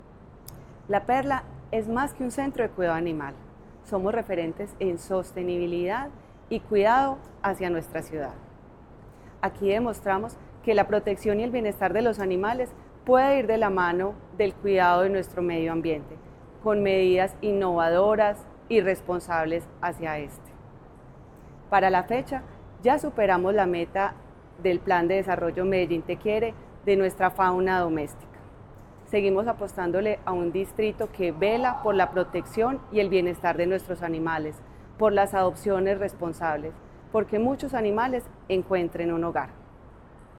Declaraciones de la subsecretaria de Protección y Bienestar Animal, Elizabeth Coral
Declaraciones-de-la-subsecretaria-de-Proteccion-y-Bienestar-Animal-Elizabeth-Coral.mp3